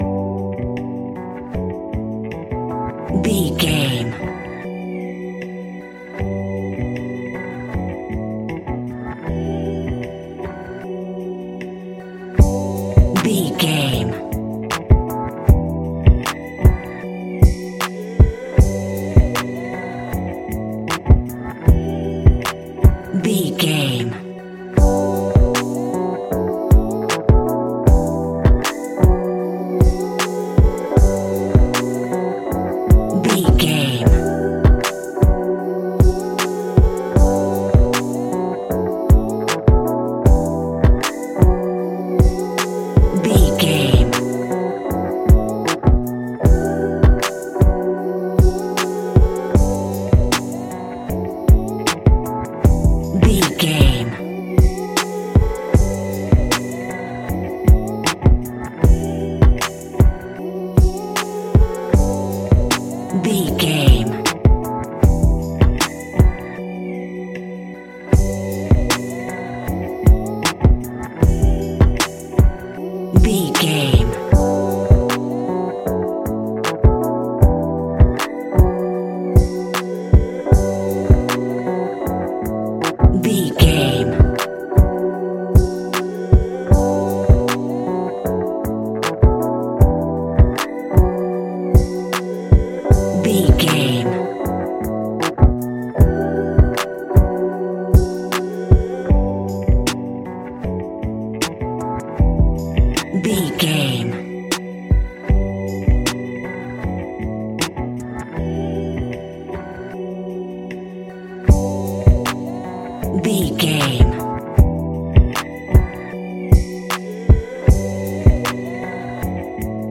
Aeolian/Minor
SEAMLESS LOOPING?
drums
dreamy
smooth
mellow
soothing
double bass
synthesiser